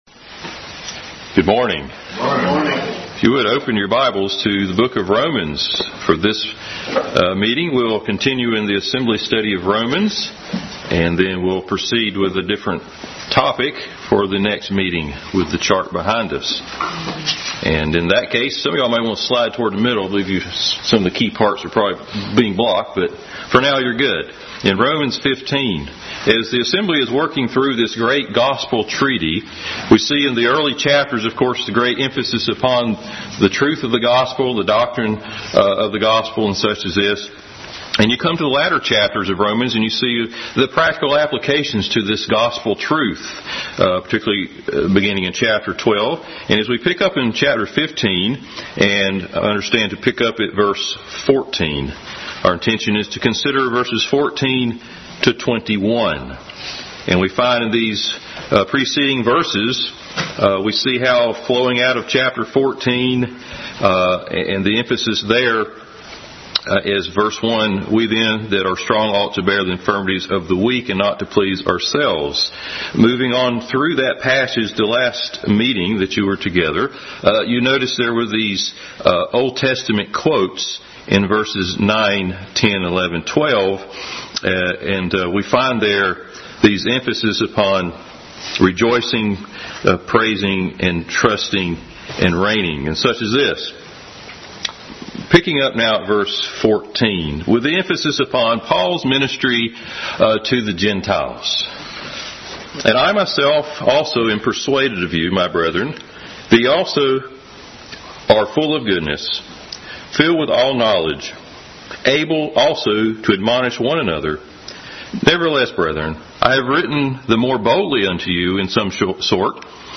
Bible Text: Romans 15:14-21, 11:13, 9:3, 10:1, 11:1-2, Acts 9:15-16, Matthew 10:5-6, 15:21-28, Ephesians2:11-13, 2 Corinthians 10:8, Isaiah 52:13-15 | Adult Sunday School continued study in the book of Romans.